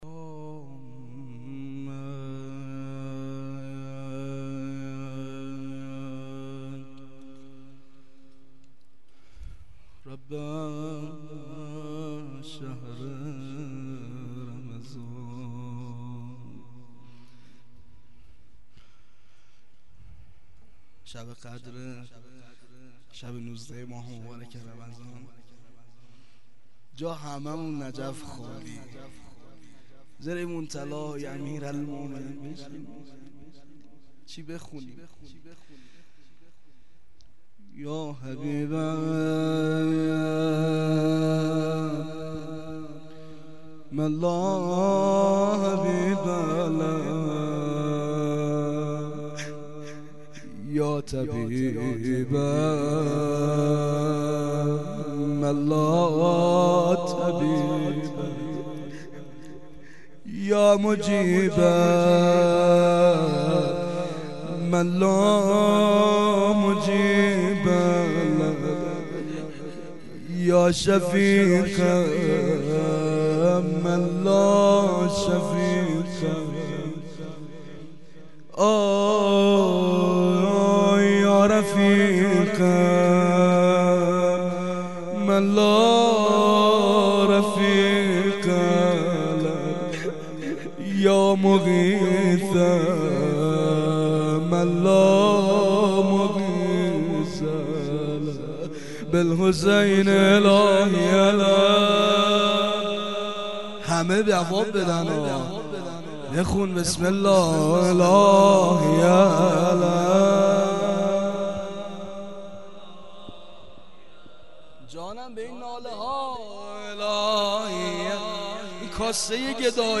مناجات و روضه